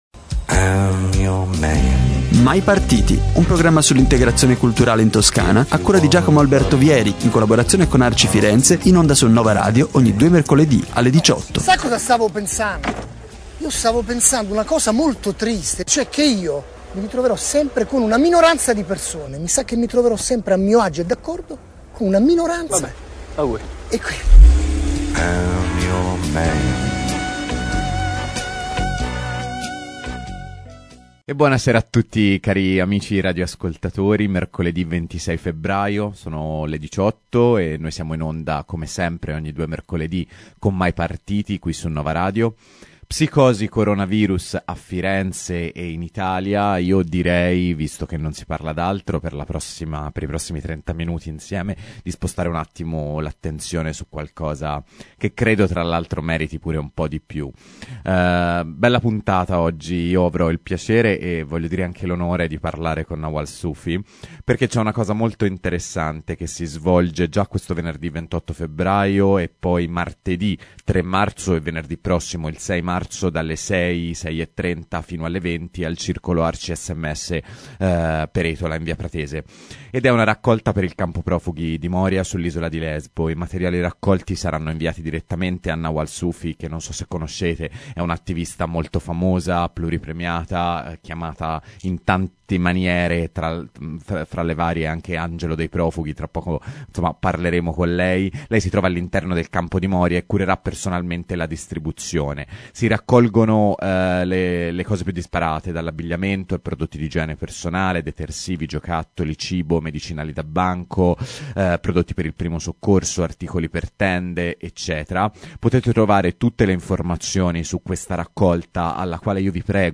Succede oggi a Lesbo – L’intervista audio